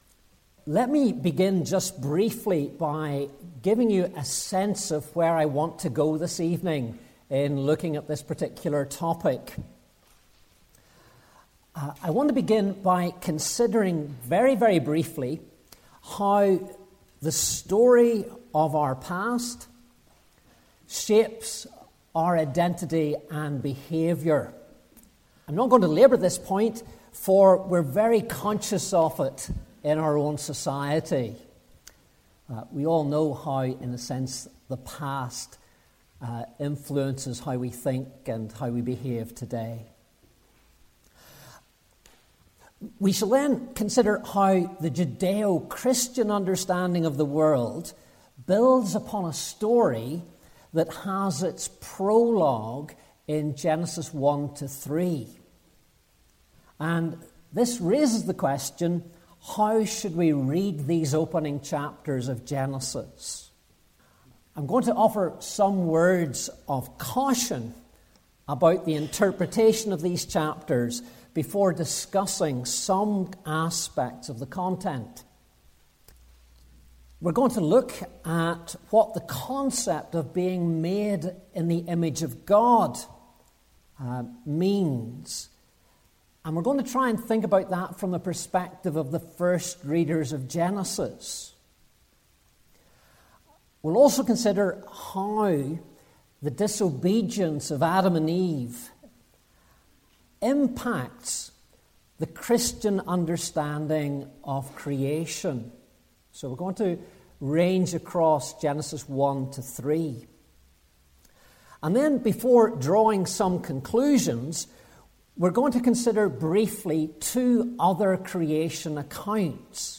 Seminar 1: What Genesis 1-3 says about human identity